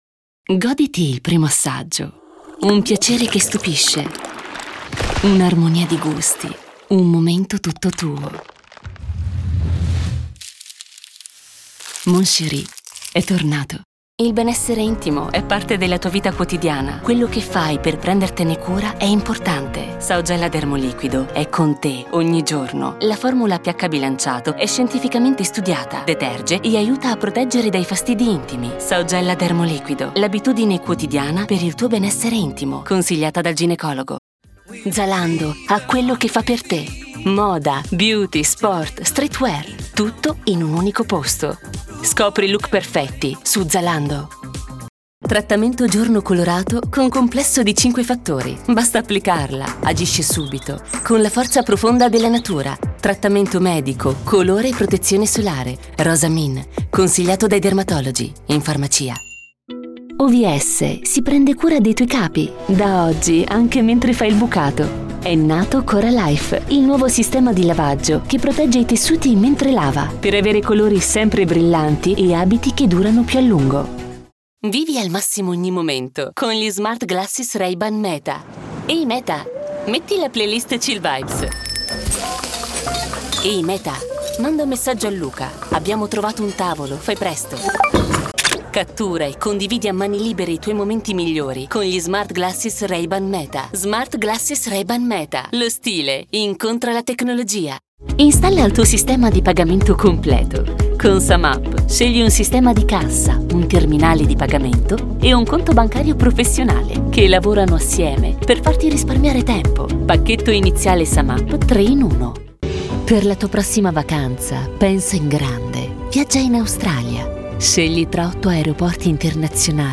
Female Voice Over | Professional Female Voice Actor | American, British, Australian, New Zealand Voice Talent
Smooth female voice over talent ready to record.